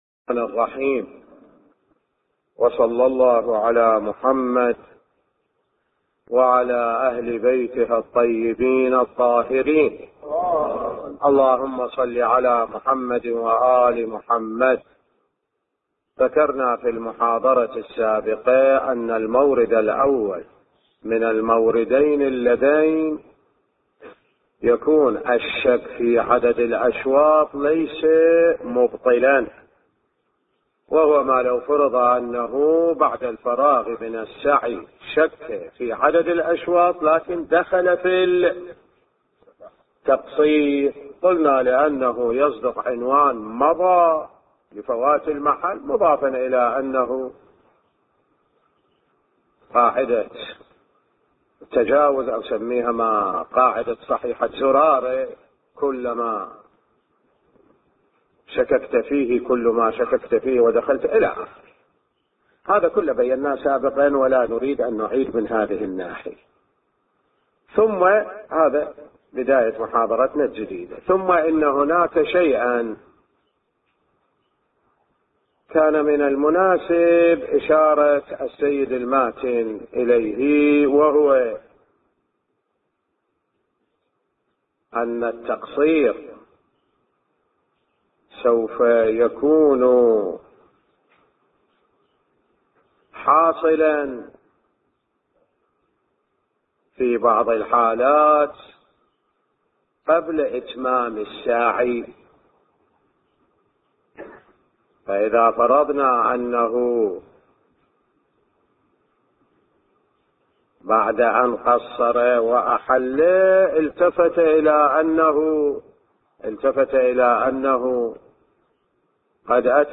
بحث الفقه